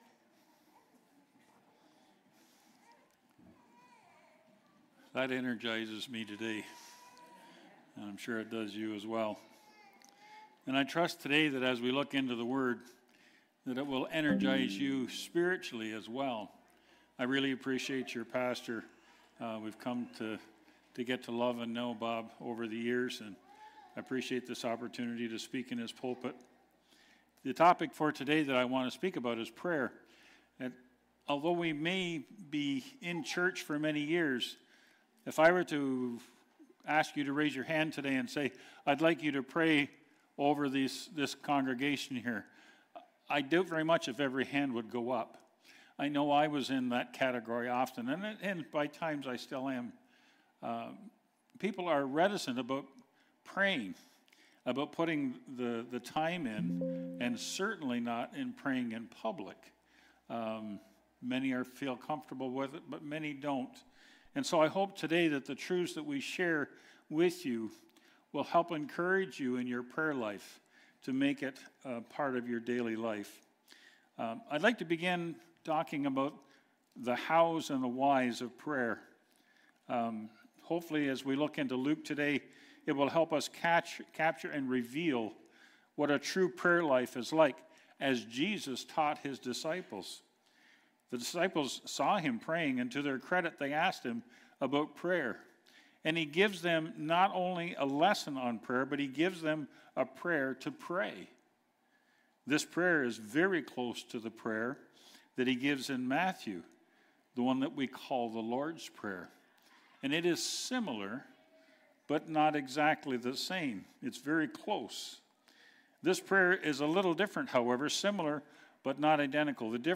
August-3rd-2025-sermon.mp3